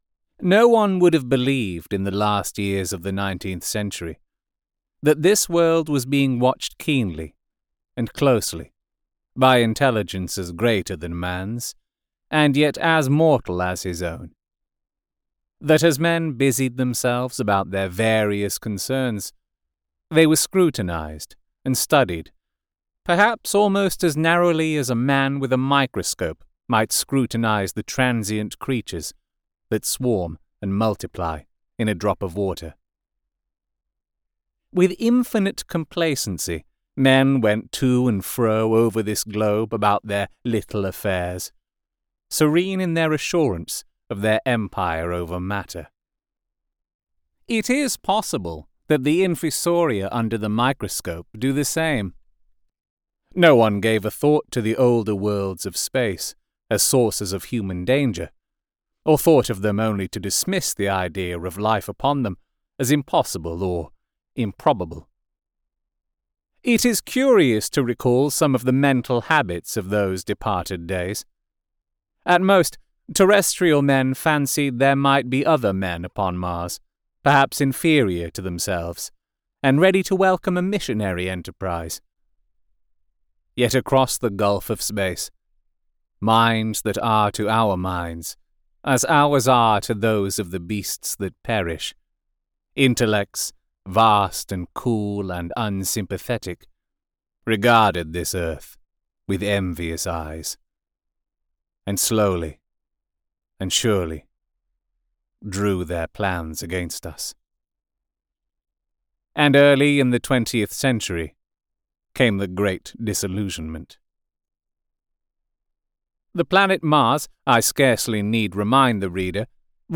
• • Author: H.G. Wells